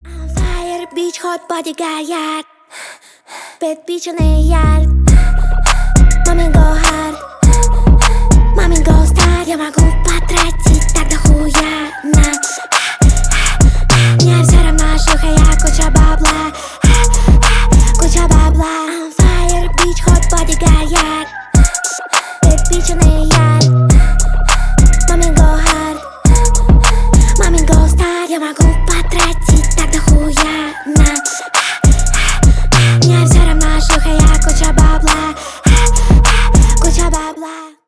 басы , женский рэп